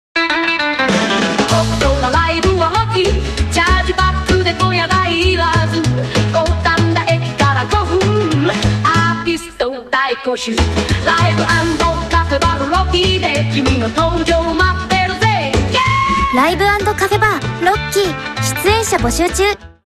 AIナレーターによるCM/ナレーション制作サービス
＜AIナレーションを利用したCM素材の例＞
上記サンプル内の歌唱もAIにより制作しています。